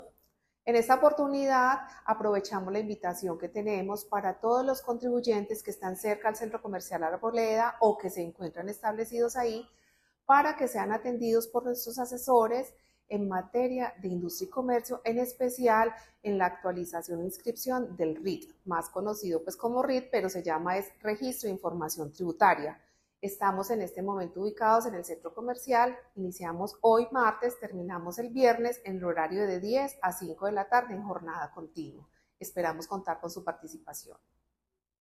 10-de-septiembre-audio-Subsecretaria-de-Asuntos-Tributarios-Luz-Adriana-Rios.mp3